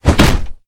club.ogg